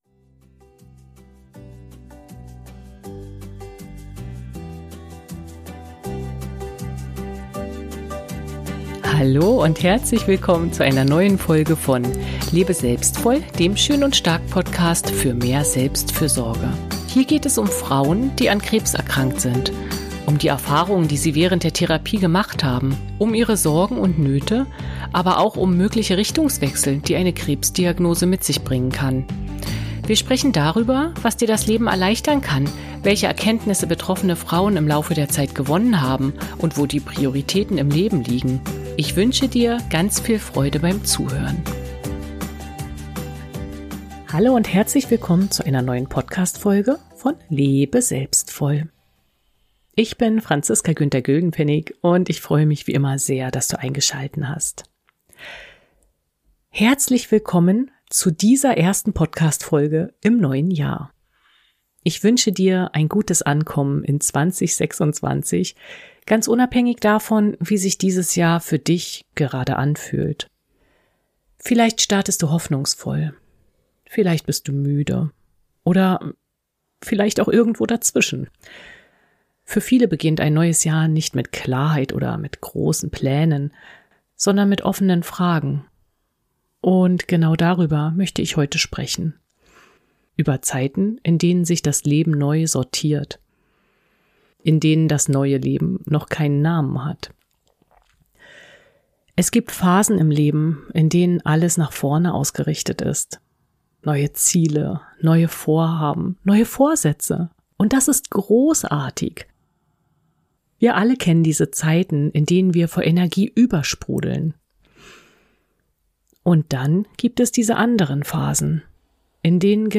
In dieser Solo-Folge spreche ich über genau diese Zwischenzeiten im Leben.